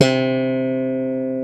JS BASS #80A.wav